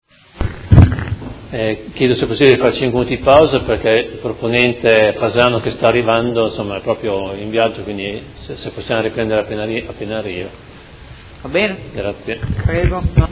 Fabio Poggi — Sito Audio Consiglio Comunale
Seduta del 13/12/2018. Ordine del Giorno presentato dai Consiglieri Fasano, Baracchi, Bortolamasi, Forghieri, Arletti, Lenzini, Venturelli, Morini, Di Padova, Pacchioni, Liotti, De Lillo e Poggi (PD) avente per oggetto: La salute mentale a Modena nel quarantennale della “Legge Basaglia”.